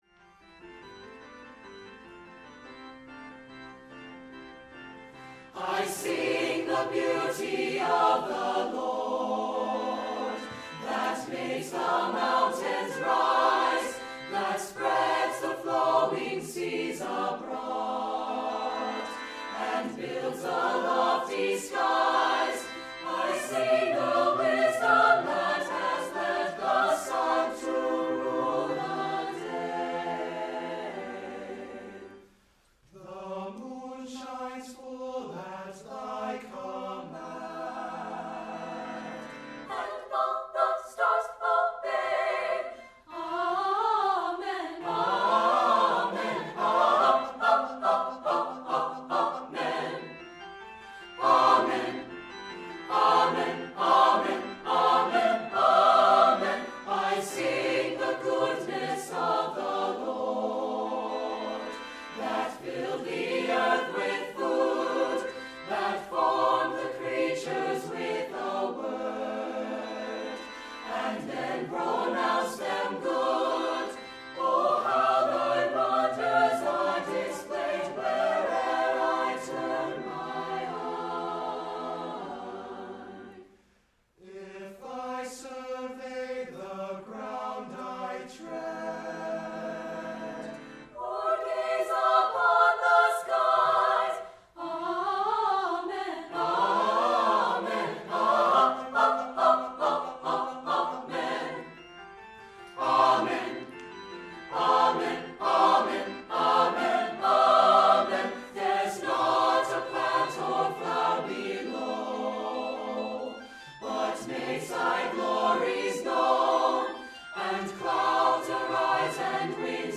for SATB Choir and Organ (2003)